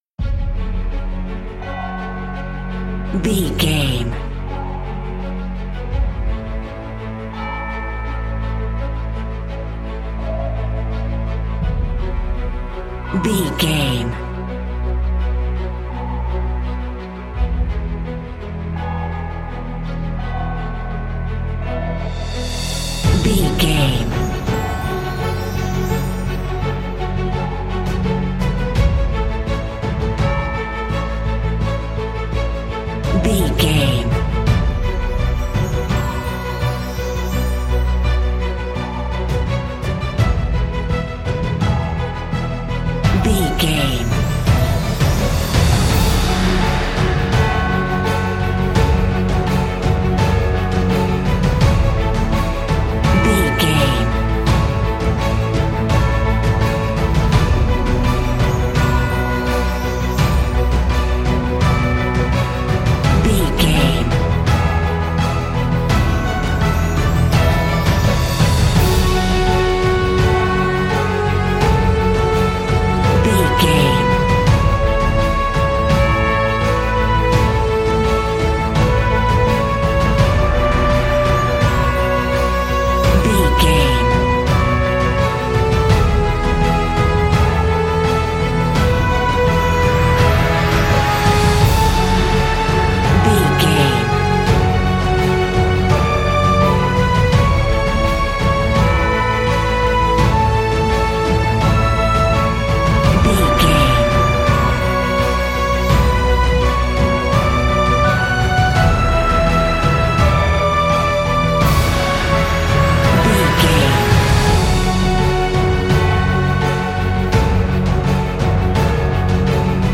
Aeolian/Minor
F♯
cinematic
brass
cello
orchestra
percussion
strings
synthesizer